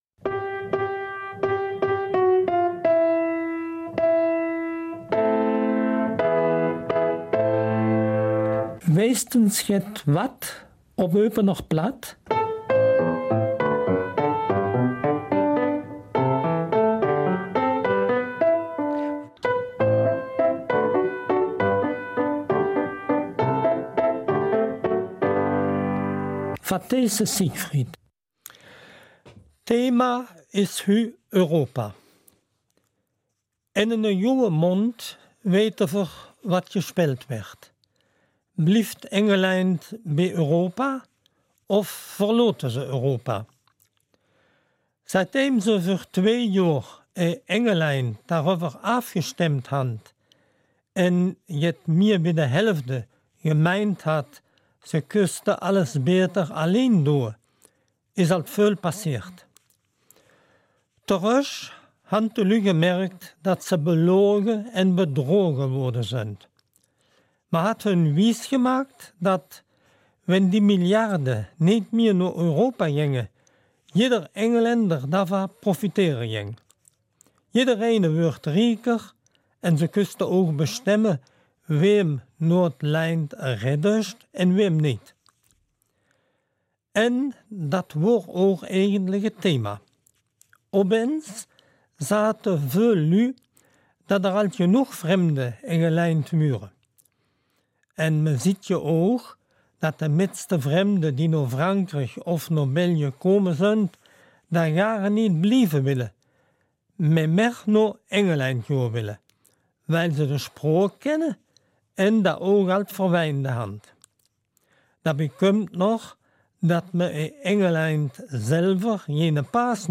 Eupener Mundartsendung zum Thema Europa